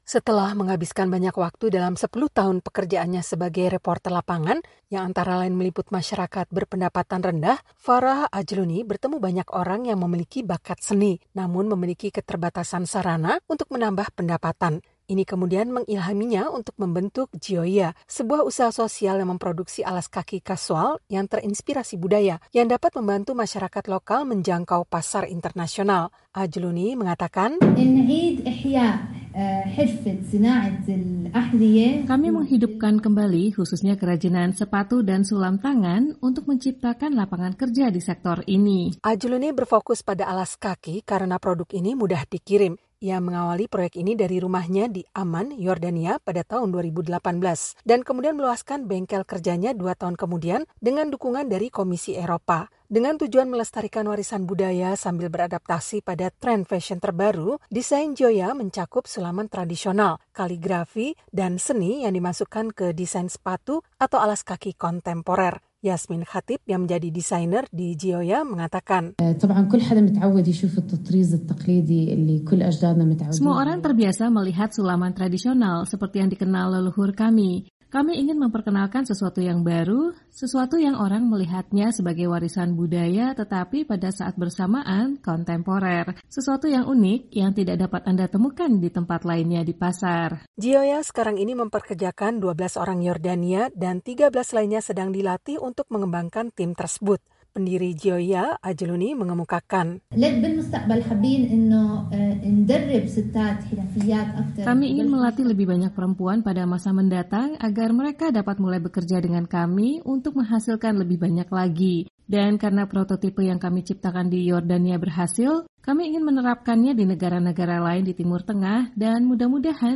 Memproduksi alas kaki kasual yang dihiasi sulaman tradisional dan kaligrafi serta melibatkan seniman lokal, ‘Gioia’ menciptakan peluang ekonomi bagi keluarga-keluarga Yordania yang berpenghasilan rendah. Berikut laporan selengkapnya bersama tim VOA.